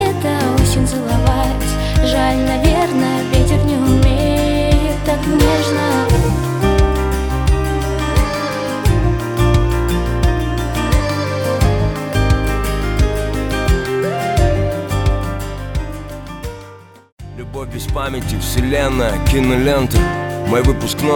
романтические , красивые , дуэт , нежные